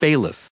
484_bailiff.ogg